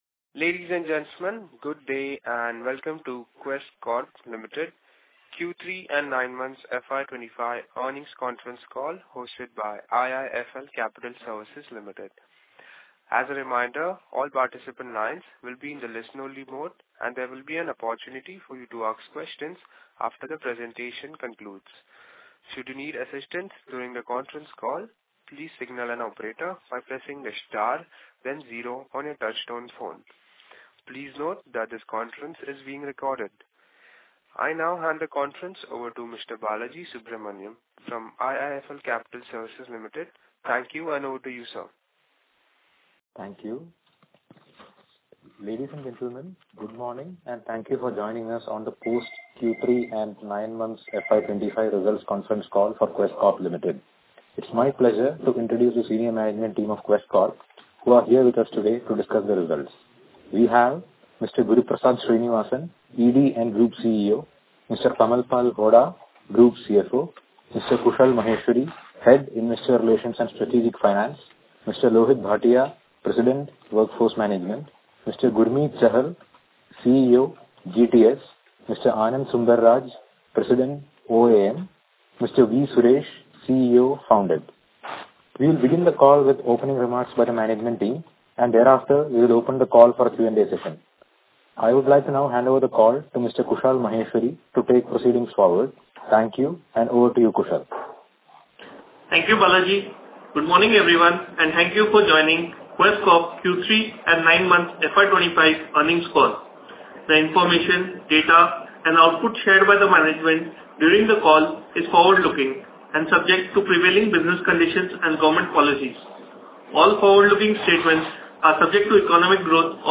Q3FY25-Earnings-call-recording.mp3